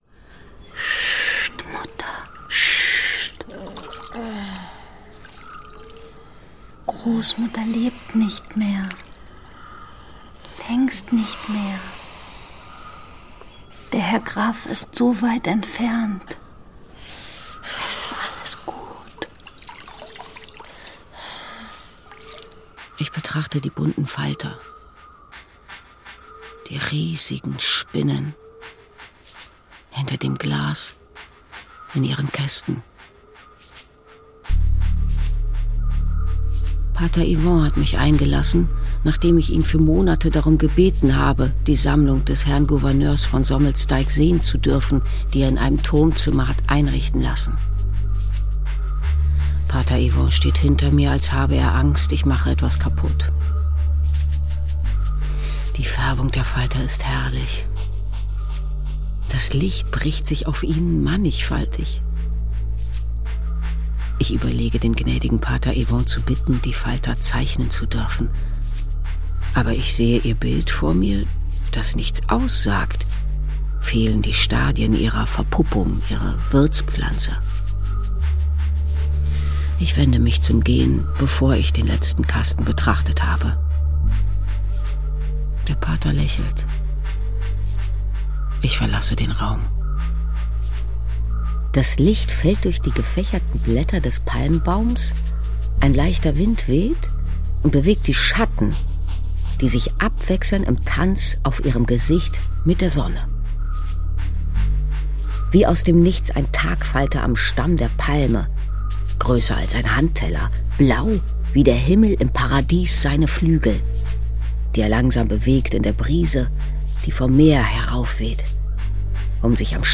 Radio | Hörspiel
FILIGRAN UND SCHWEBEND
Diese filigranen und schwebenden Zwischentöne untermalten nicht etwa die Biografie der Merian, sie schufen vielmehr ein ganz eigenes akustisches Zwischenreich.“